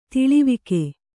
♪ tiḷivike